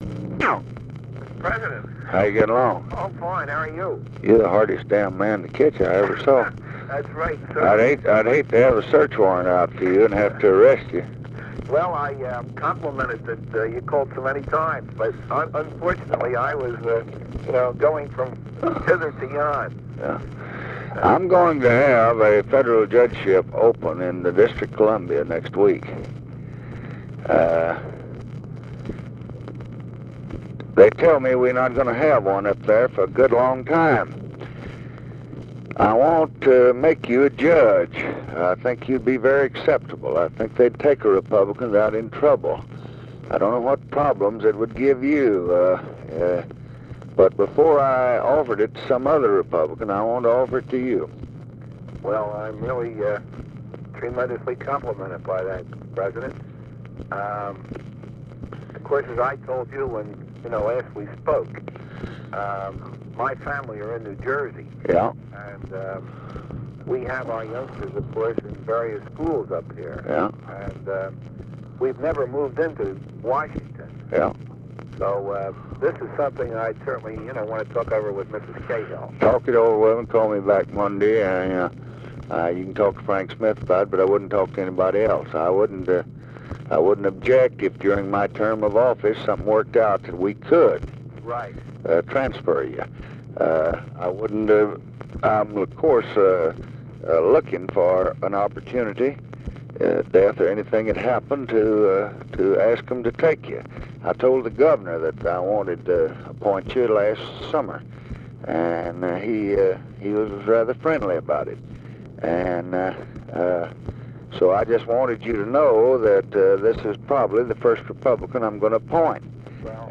Conversation with WILLIAM CAHILL, February 20, 1965
Secret White House Tapes | Lyndon B. Johnson Presidency Conversation with WILLIAM CAHILL, February 20, 1965 Rewind 10 seconds Play/Pause Fast-forward 10 seconds 0:00 Download audio Previous Conversation with WILLIAM CAHILL?